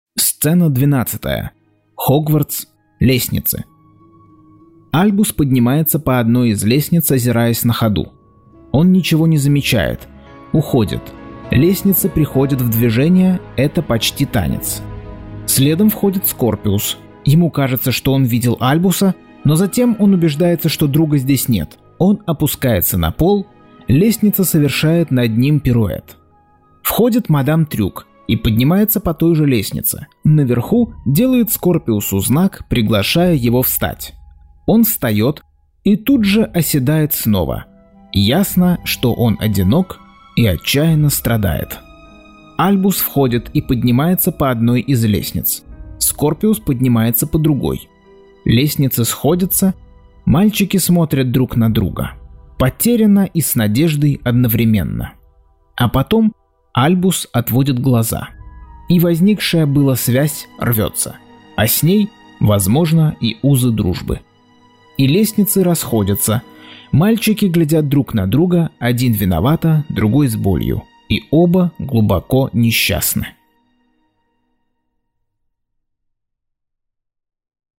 Аудиокнига Гарри Поттер и проклятое дитя. Часть 24.